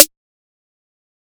Snares
Snare (6).wav